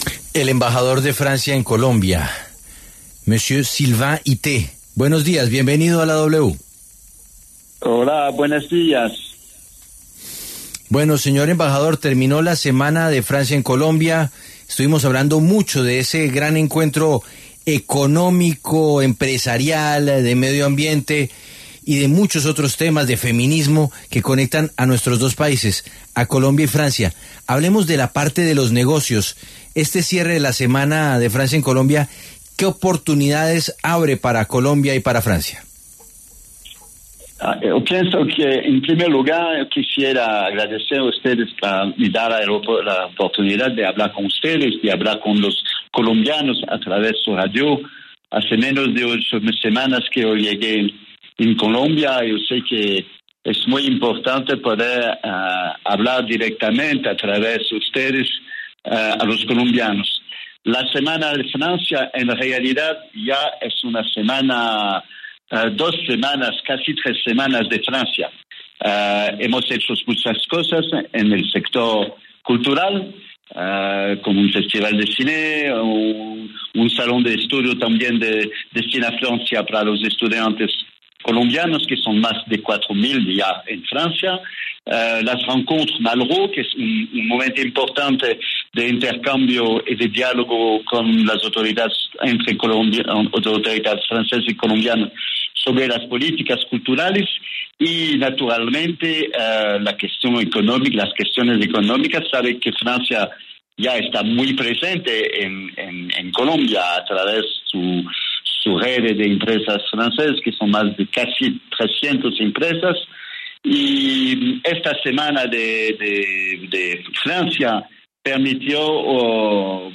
En diálogo con La W, el embajador Sylvain Itté hizo un balance sobre la Semana de Francia en Colombia.